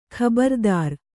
♪ khabardār